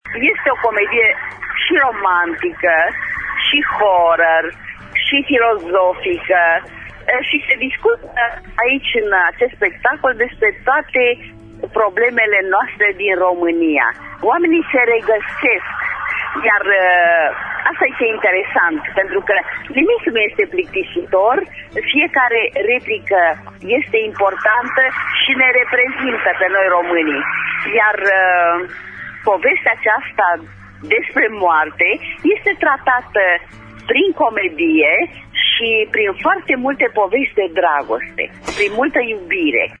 Prin telefon pentru emisiunea Pulsul Zilei, marea actriţă Rodica Popescu Bitănescu.